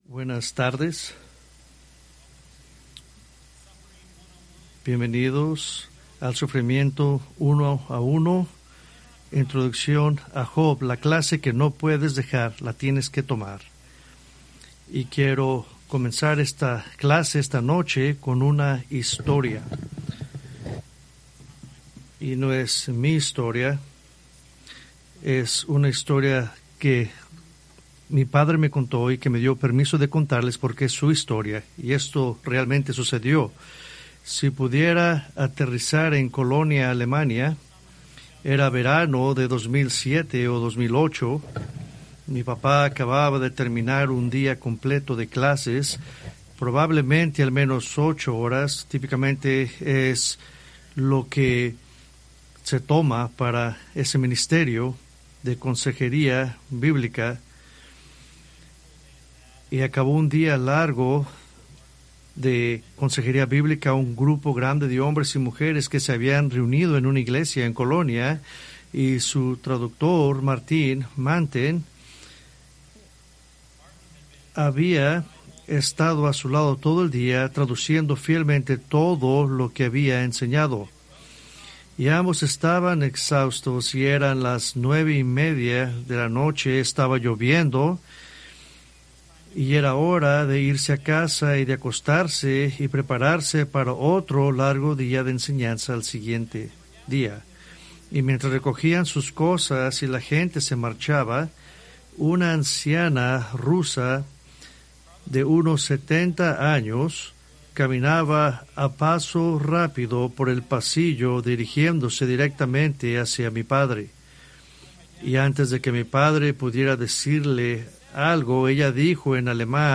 Preached March 8, 2026 from Job